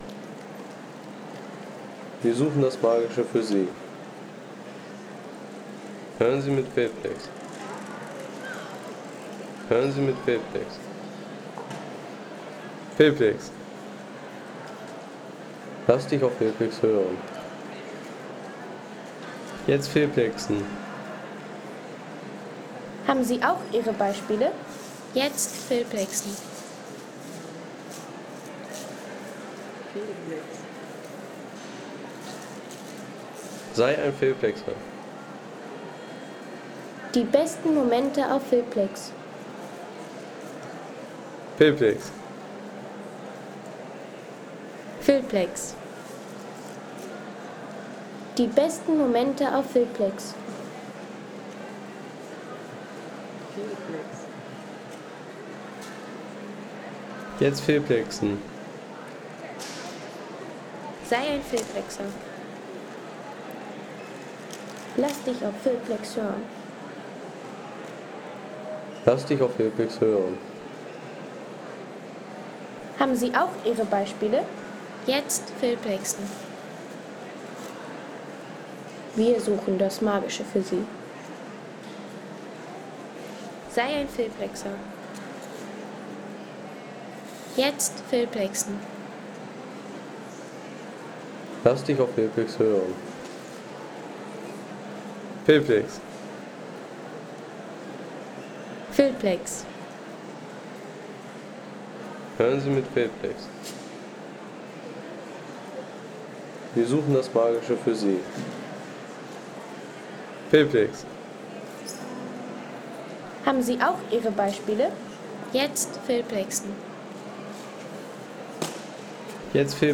Der Flughafen Mailand-Malpensa – Klänge am Check-In-Schalter.